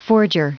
Prononciation du mot forger en anglais (fichier audio)
Prononciation du mot : forger